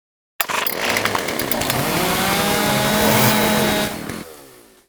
revving noise as it starts up and spins), after which he thrusts it forward, stunning and damaging any enemy player slow, unaware, or simply unfortunate enough to be in the way.